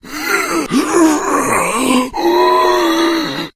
fracture_attack_3.ogg